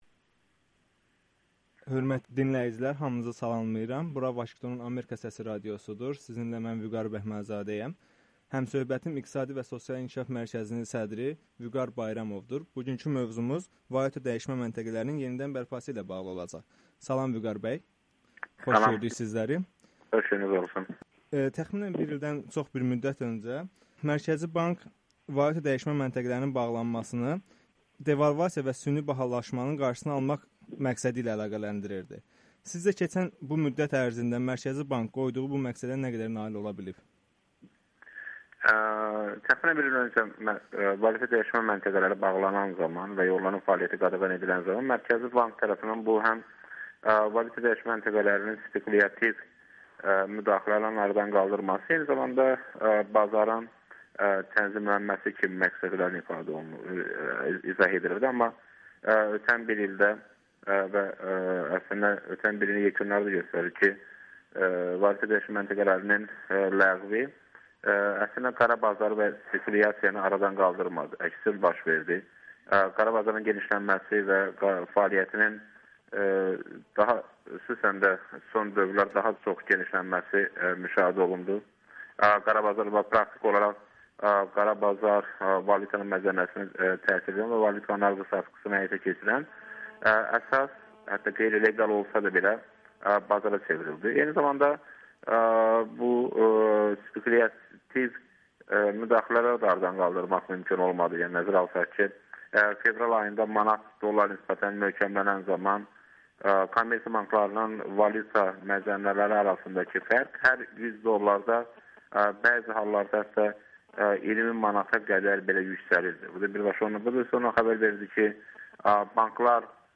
İqtisadçı Vüqar Bayramov Amerikanın Səsinə müsahibədə valyutadəyişmə məntəqələrinin yenidən açılması haqda danışır